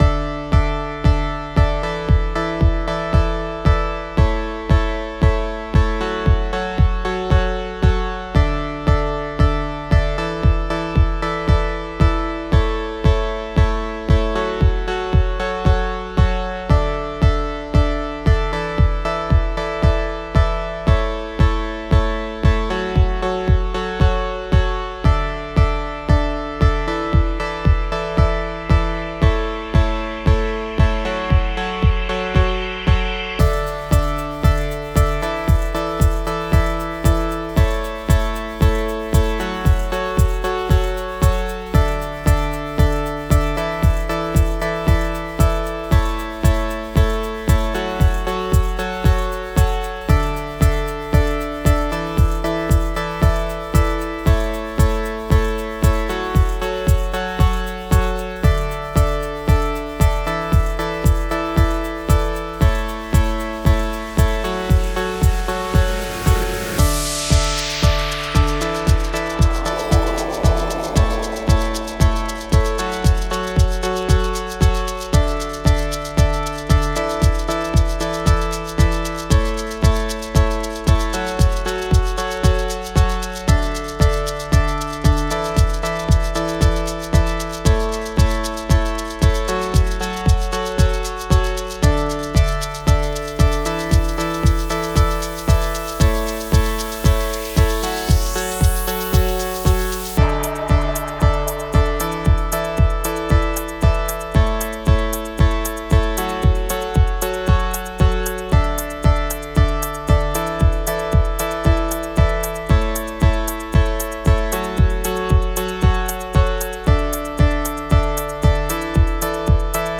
Los acompañamientos:
El primero a 115 la negra, más lento que el original.